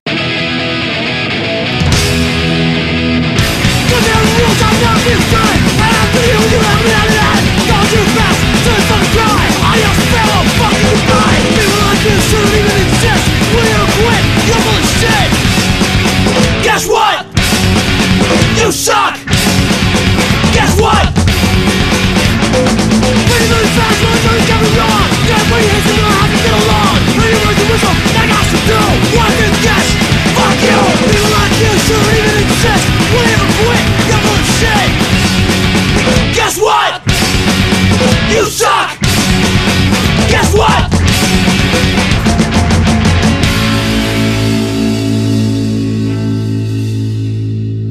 Punkhardcore da Roma